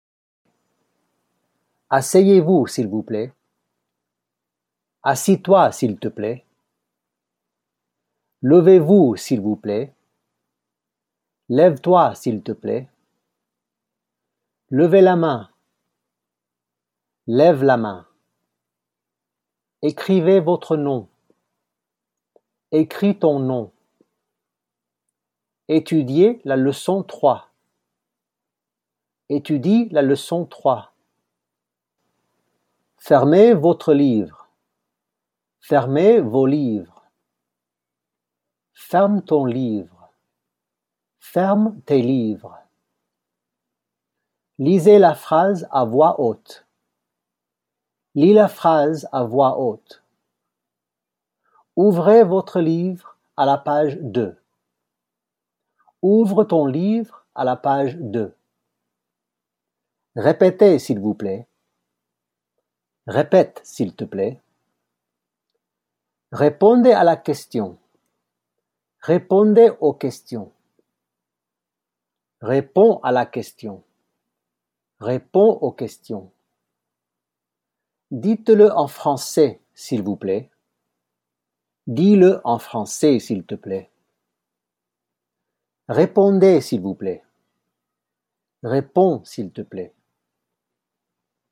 Note on pronunciation: when you listen to the vocabulary, pay attention on how most final consonants of words are NOT pronounced.